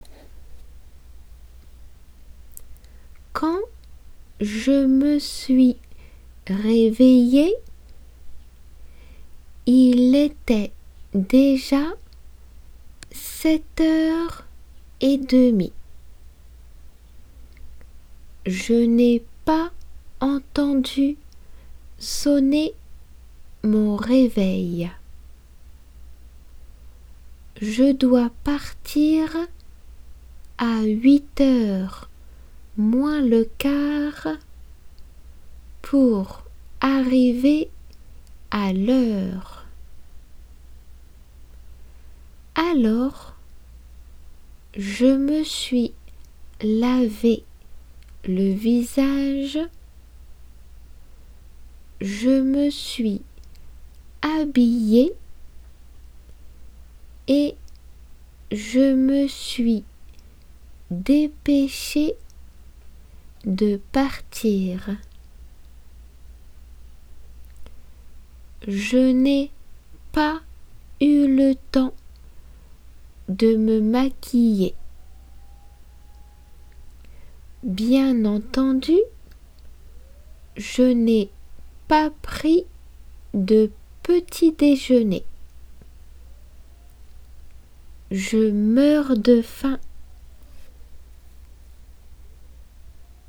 普通の速さで。